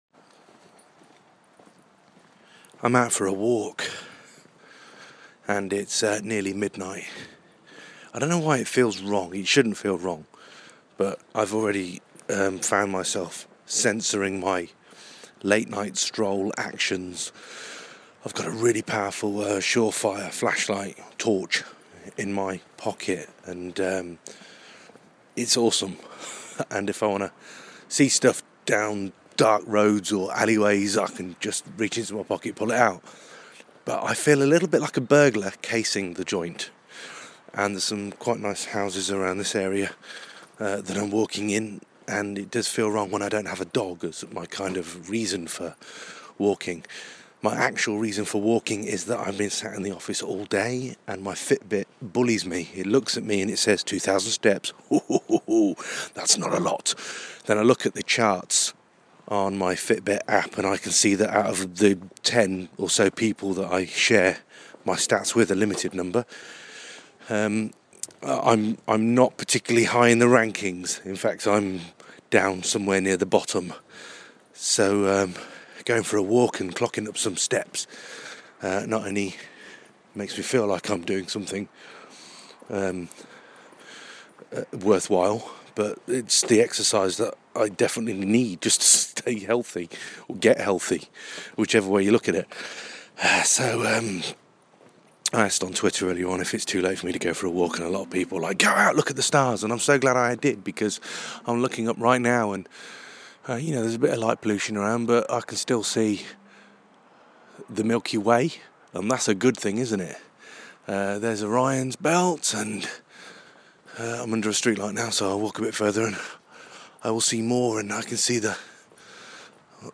In an attempt to clock up some much needed fitbit stats I head out for a midnight walk looking for peanuts.